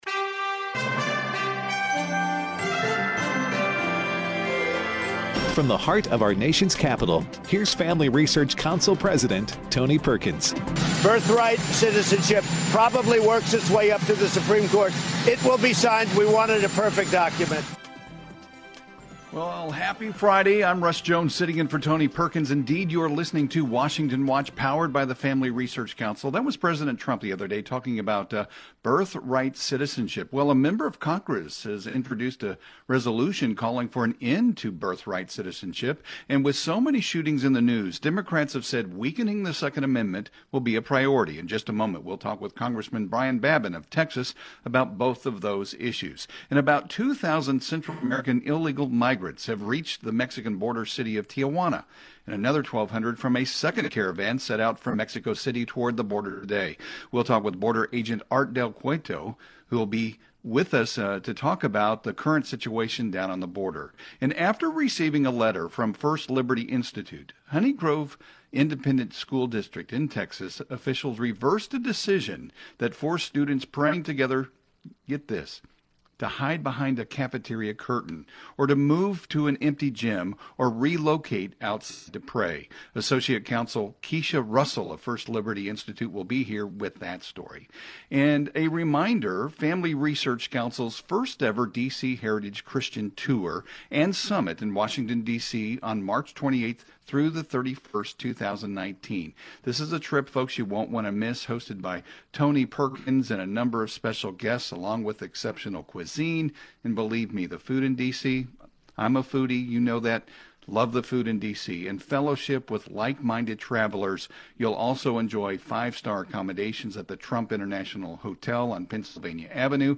Rep. Brian Babin (R-Texas) joins our guest host to share a resolution he introduced calling for an end to birthright citizenship.